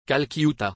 kalkiuta or
splat_calcutta_pronounciation1.mp3